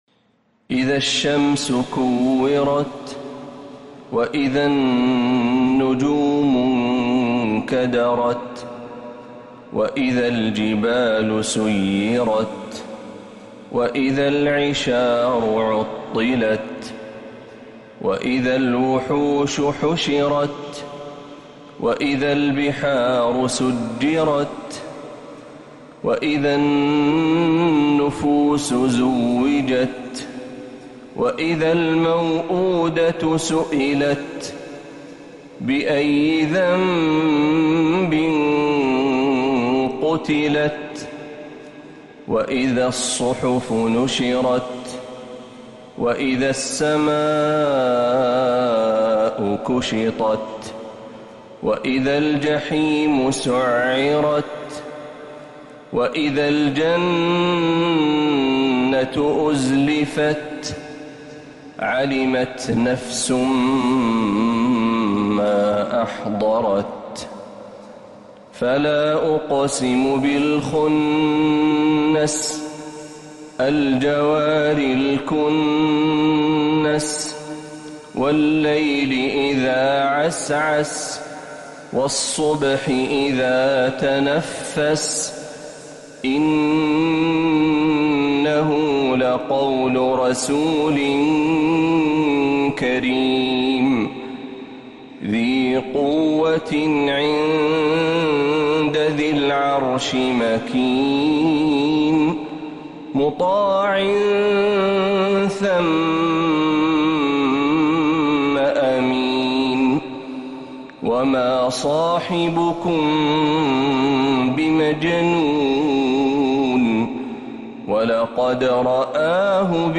سورة التكوير كاملة من الحرم النبوي